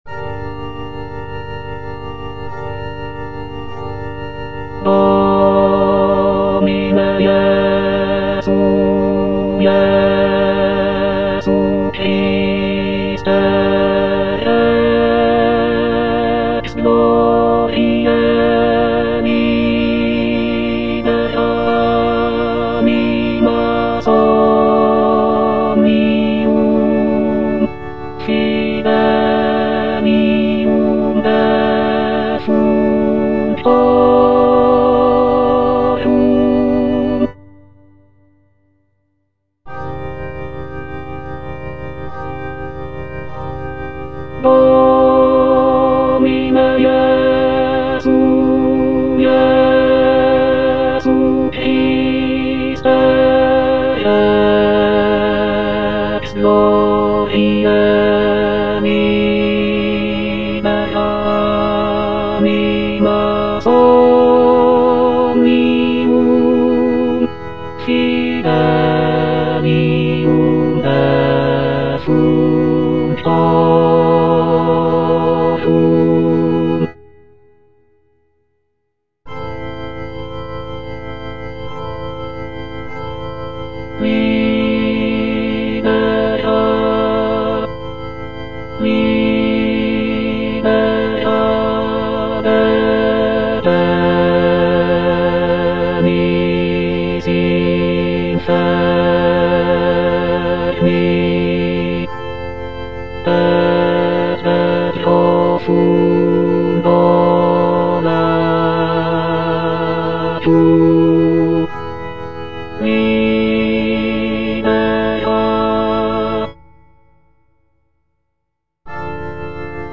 F. VON SUPPÈ - MISSA PRO DEFUNCTIS/REQUIEM Domine Jesu - Bass (Emphasised voice and other voices) Ads stop: auto-stop